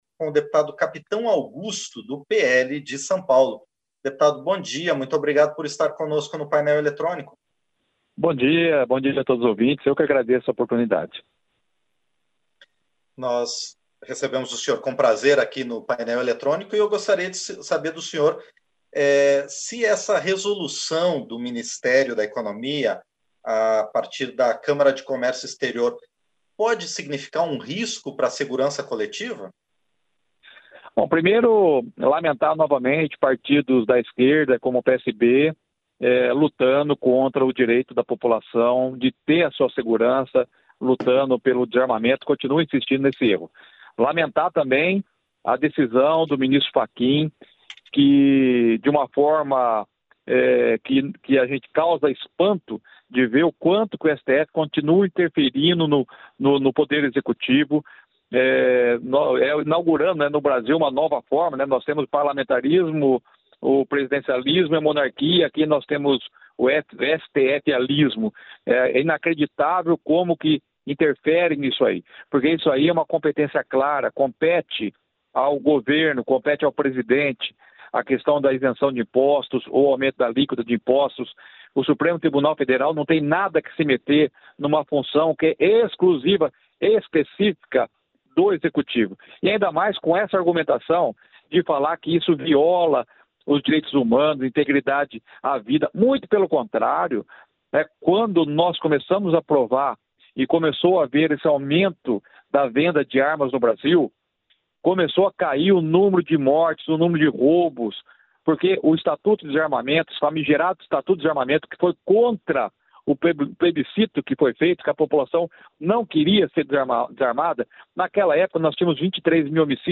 Entrevista - Dep. Capitão Augusto (PL-SP)